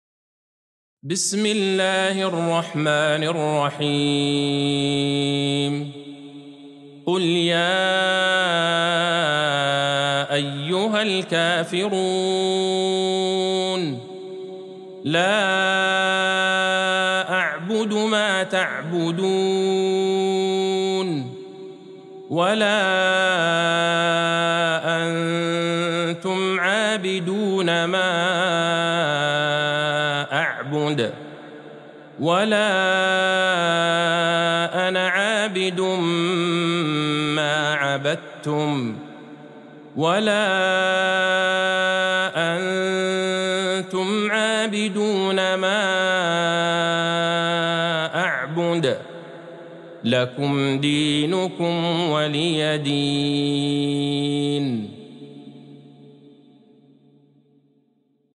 سورة الكافرون Surat Al-Kafirun | مصحف المقارئ القرآنية > الختمة المرتلة